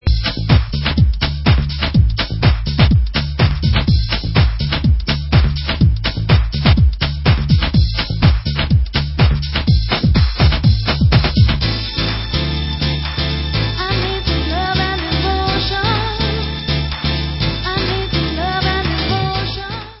Vocal house